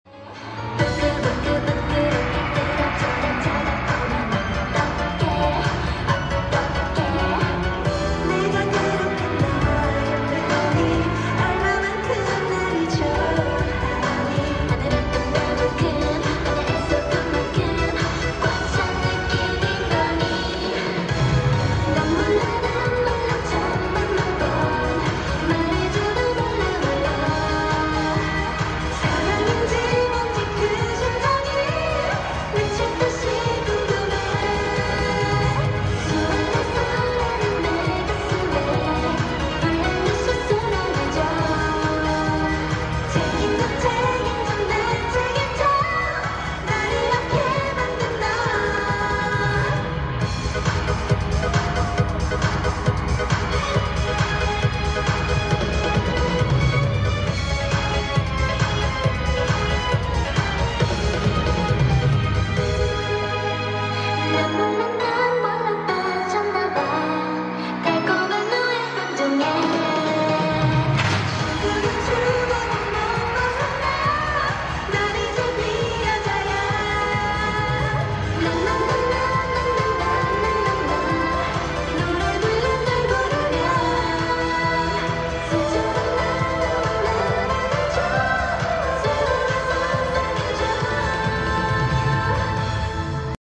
Fancam | The Fan Concert